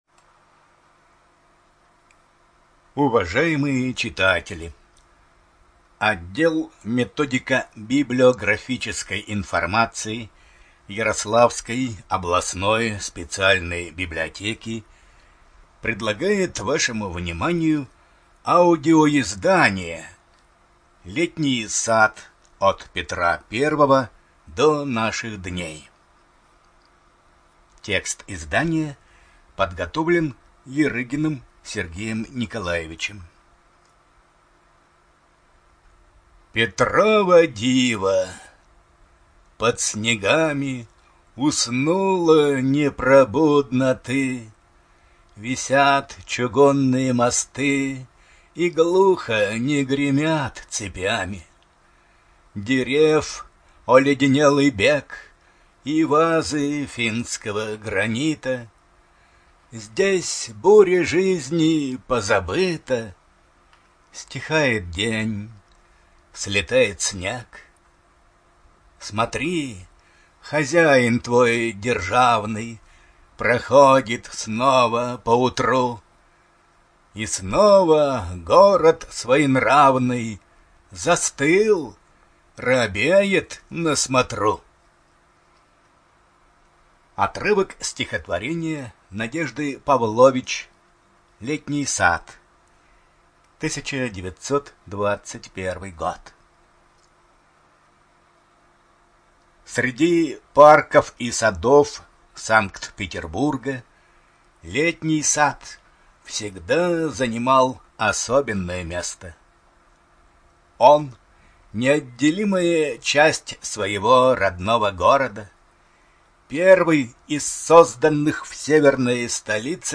Студия звукозаписиЯрославская областная библиотека для слепых